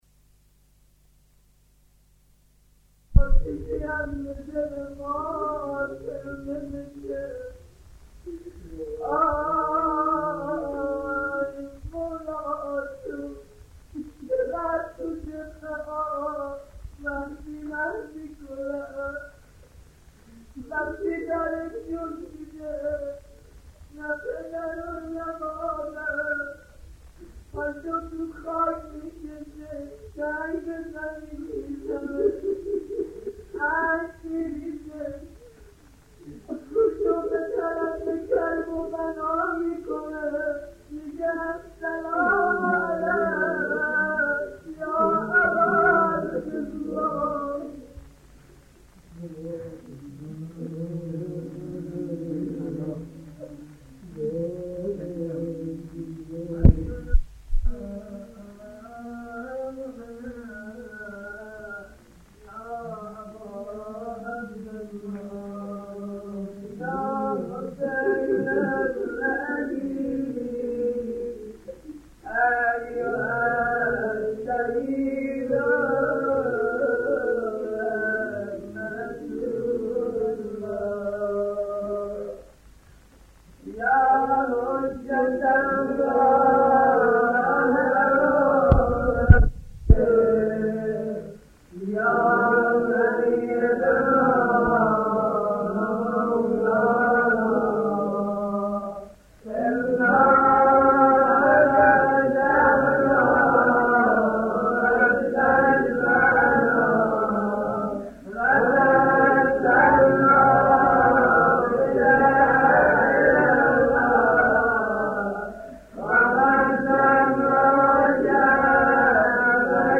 قرائت دعای توسل